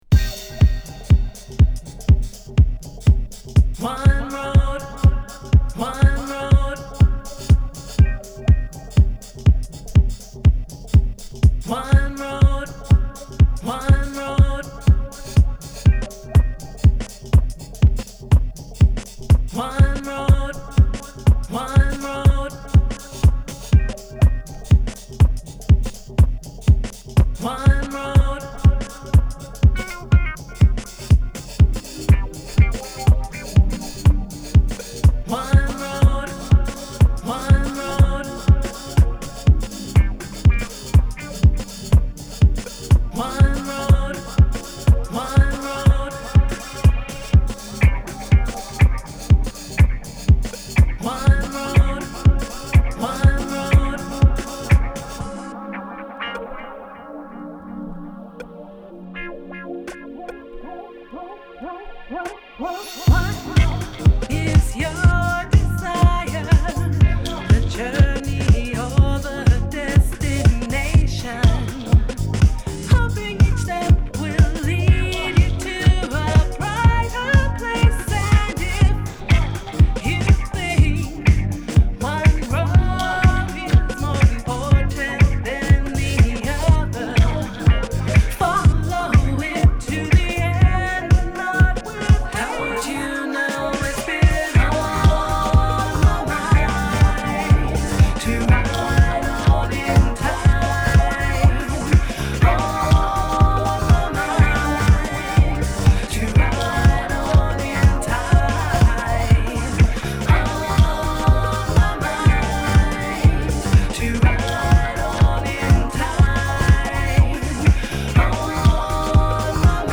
煌びやかなギターソロやダブ処理もバランスよく聴かせてくれます。